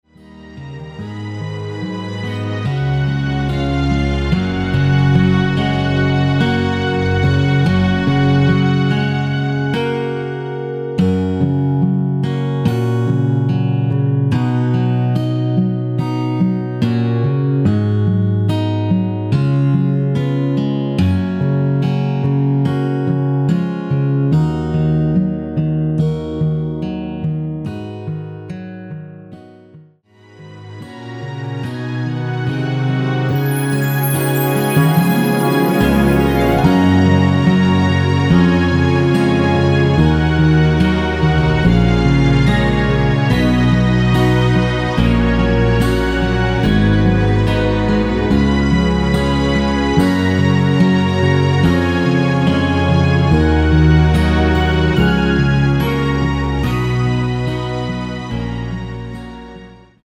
원키에서(+2)올린 MR입니다.
F#
앞부분30초, 뒷부분30초씩 편집해서 올려 드리고 있습니다.
중간에 음이 끈어지고 다시 나오는 이유는